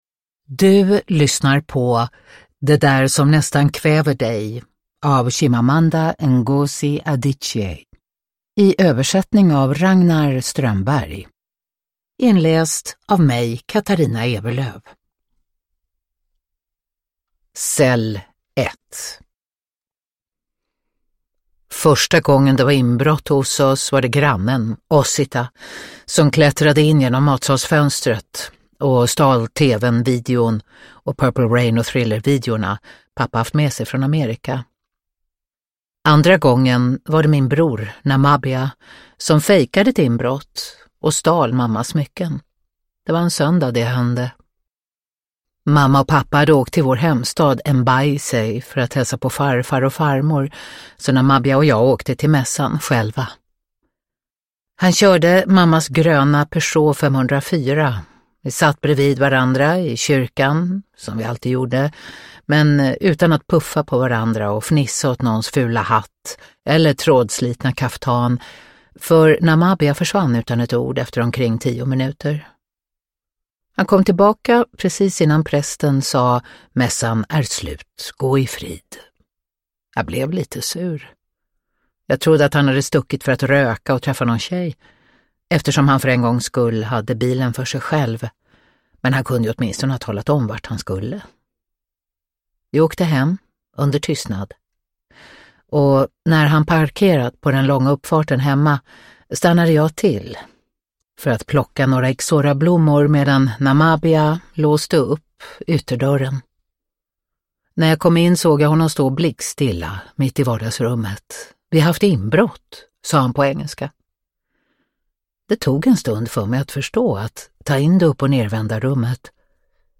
Det där som nästan kväver dig – Ljudbok – Laddas ner